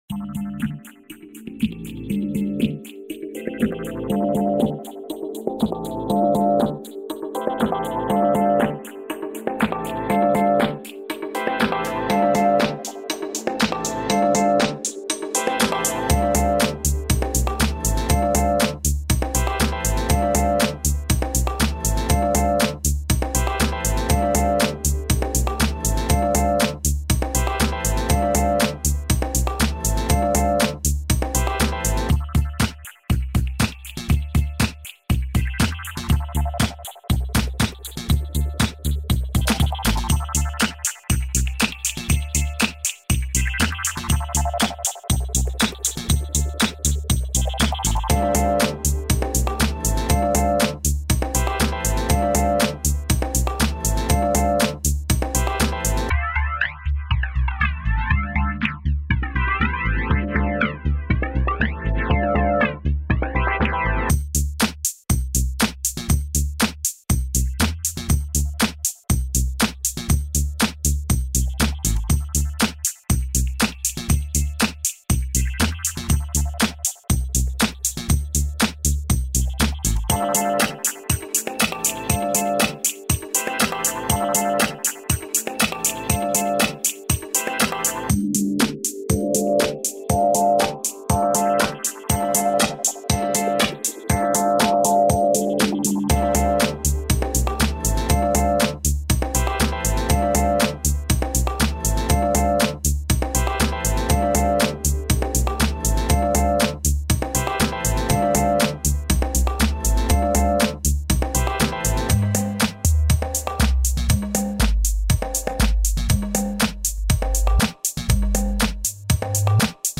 PSP Rhythm is a homebrew drum machine for the Sony Playstation Portable.